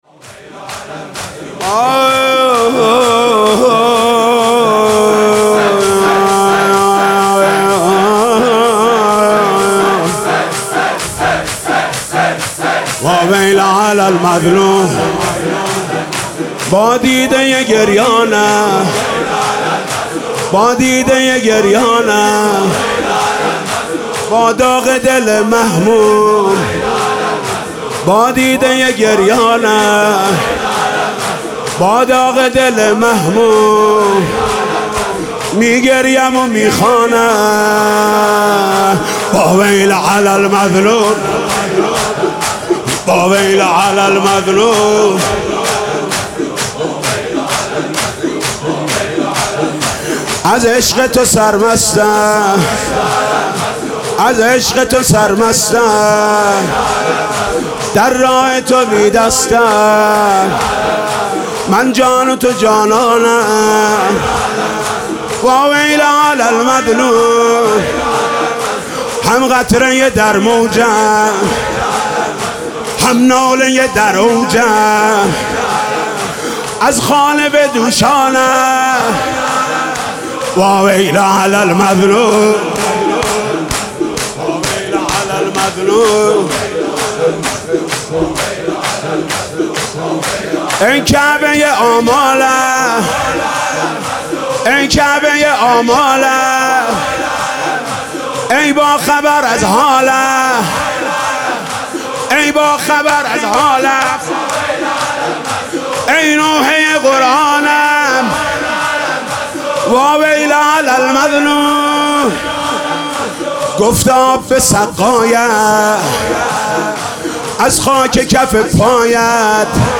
«محرم 1396» (شب تاسوعا) شور: واویلا علی المظلوم
«محرم 1396» (شب تاسوعا) شور: واویلا علی المظلوم خطیب: حاج محمود کریمی مدت زمان: 00:04:04